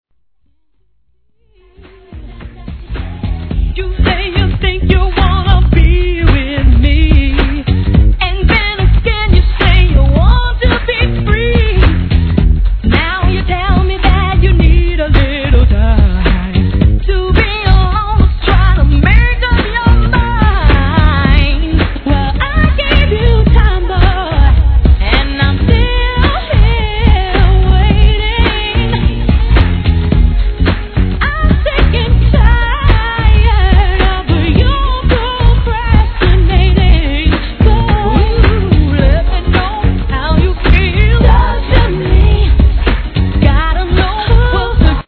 HIP HOP/R&B
グランドビート調のダンサンブルなトラックに力強く伸びやかなヴォーカルで歌い上げた力作！！